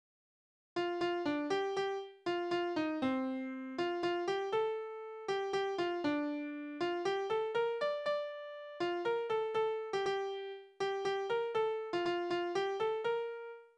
Tonart: B-Dur
Taktart: 3/4, 4/4
Tonumfang: große None
Besetzung: vokal
Anmerkung: die Taktart wechselt im Stück kurzzeitig zu 4/4